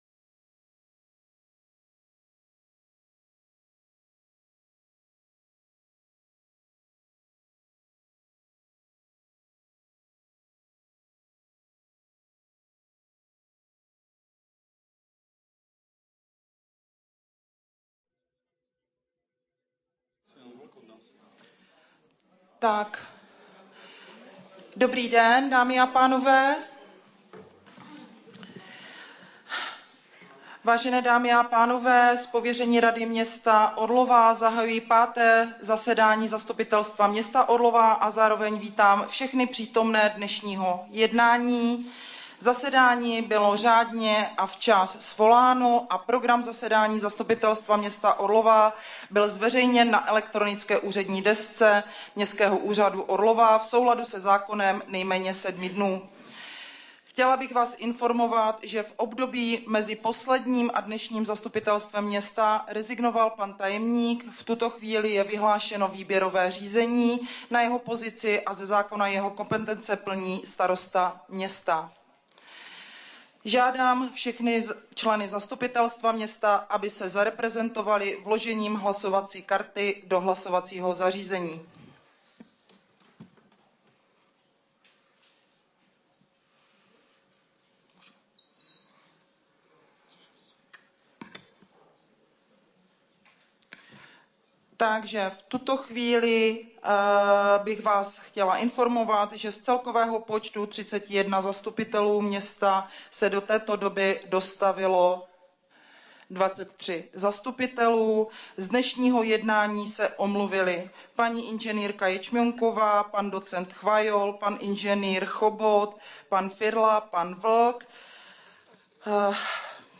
V. zasedání Zastupitelstva města Orlové